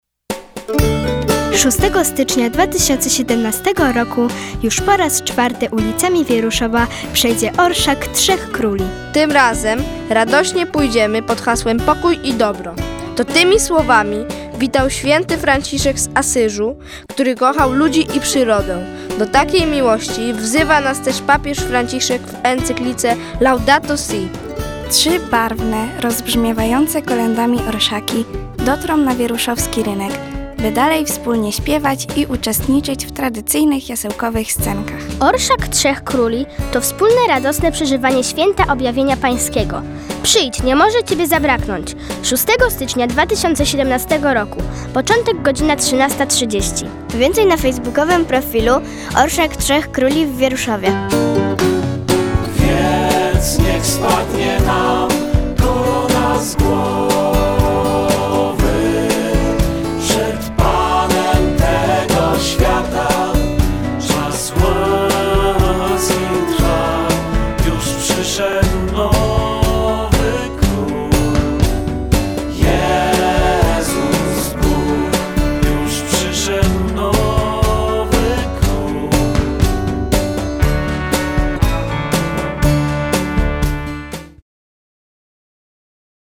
Jingiel-Orszak-trzech-króli-2017.mp3